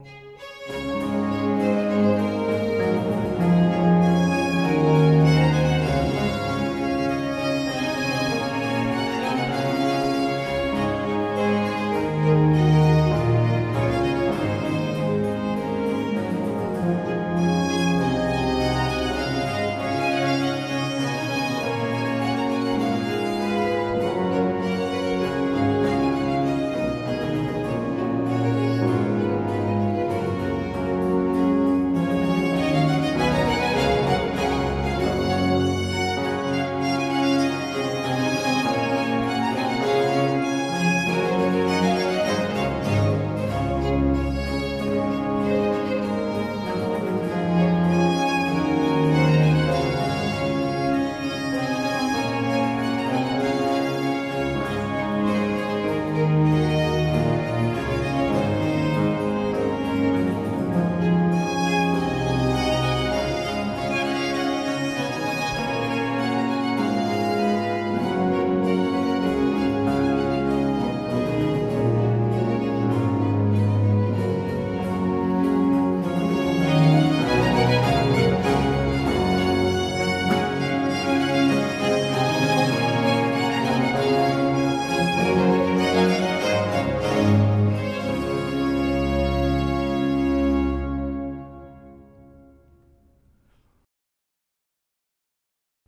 03.-trio-o-di-notte-felice-introd.-strum..mp3